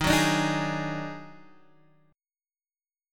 D#mM11 chord